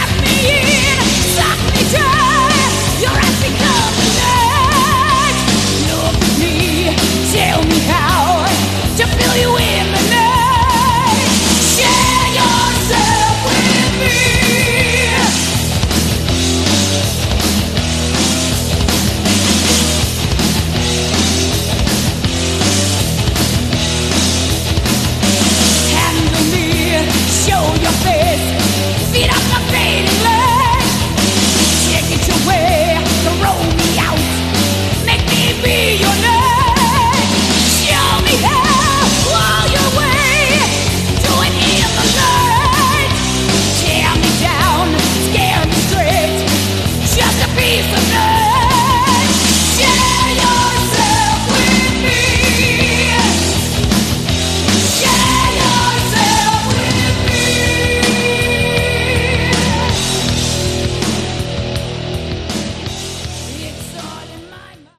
Category: Melodic Metal
guitar
vocals
drums
bass